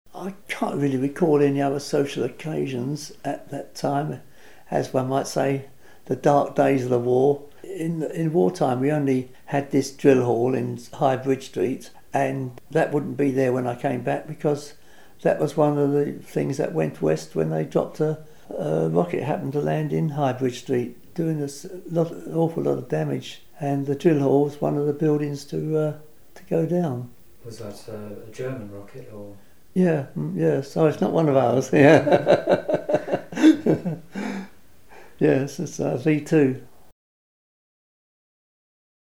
WAOH - Waltham Abbey Oral History
Gunpowder Park CD Interviews